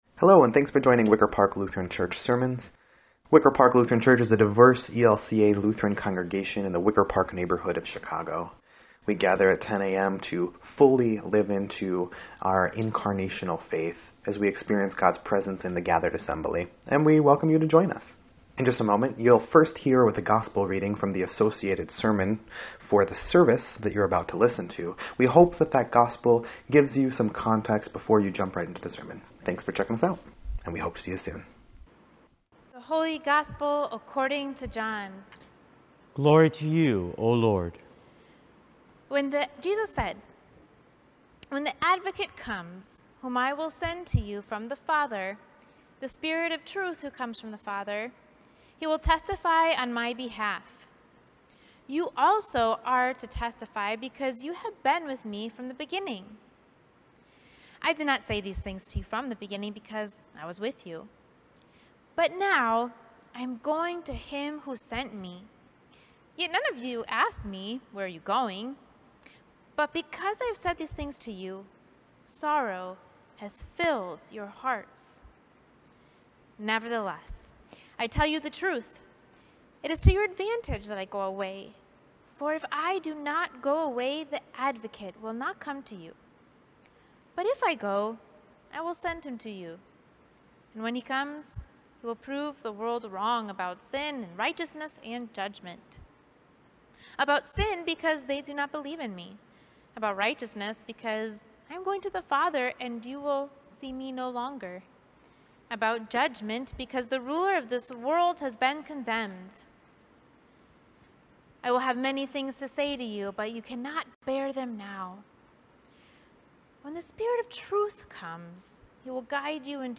5.23.21-Sermon_EDIT.mp3